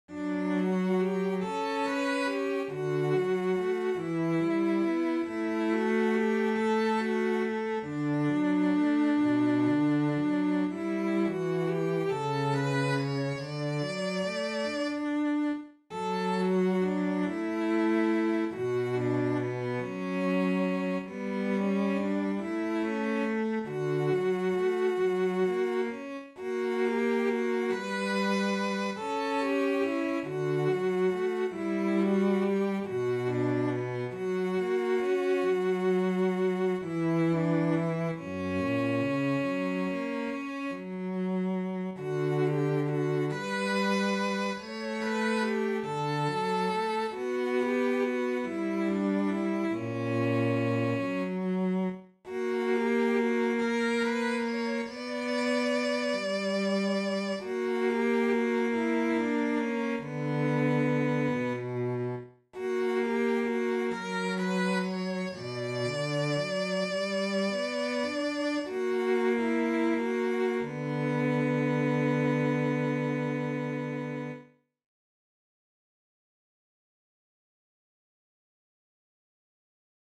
Valoa-varjoihin-sellot.mp3